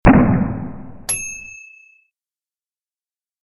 Shotgun Or Rifle LOUD (Shot 1 Time) Sound Effect
A very CLEAN recording of a LOUD shotgun being fired one time.
loudshotgun1timenew.mp3